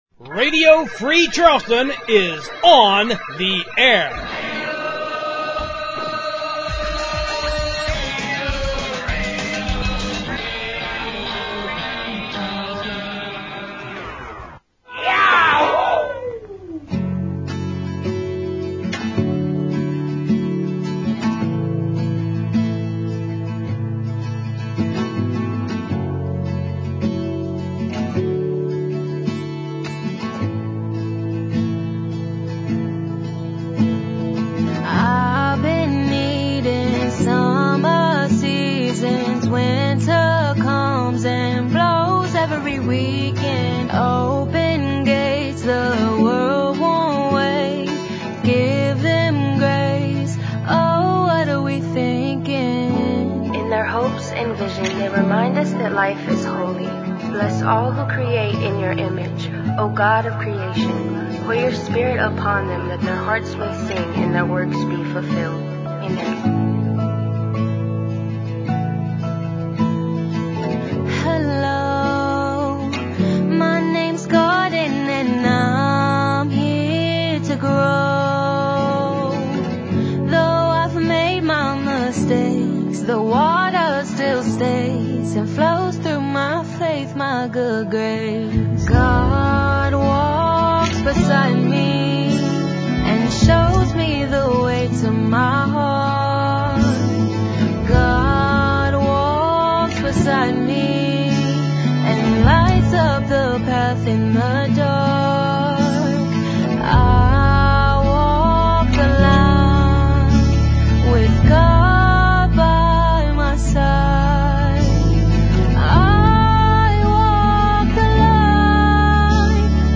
I open the show with a new song, plus a new set of six tunes, and then we go back to earlier this year for chunks of previous shows–the best chunks–I’m talking USDA Grade A chunks of internet radio–so you can revisit the vital and thriving local and independent music scene that our free-format show brings you each week.